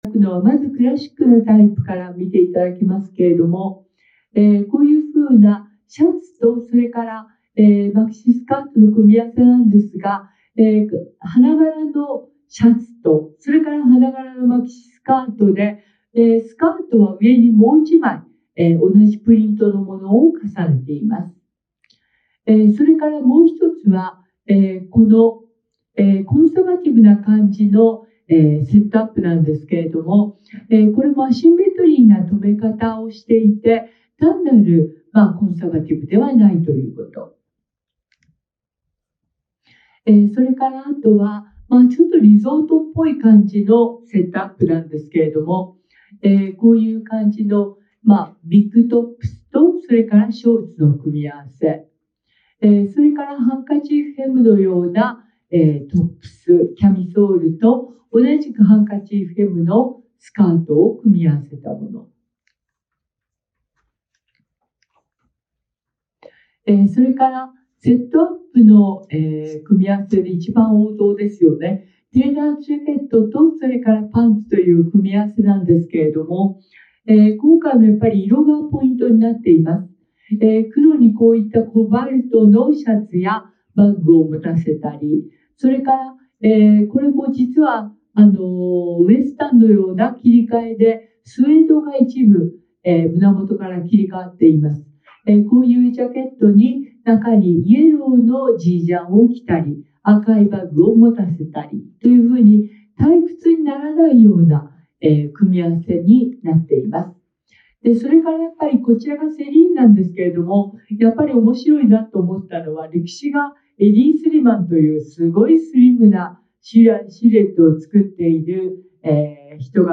【新宿ピカデリーにて実施】
E.2025年10月30日 演台 iPhone 収録音声